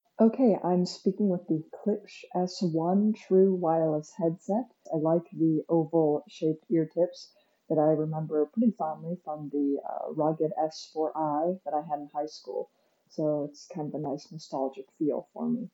The microphone system is just okay, and arguably no better than using your handset’s microphone.
Yet this tuning doesn’t prevent speakers from sounding can make someone sound “muffled,” which may accurately describe the microphone demo below.
Klipsch S1 True Wireless microphone demo:
This microphone is passable for casual use but there are better wireless headsets for conference calls.
Klipsch-S1-True-Wireless-microphone-demo.mp3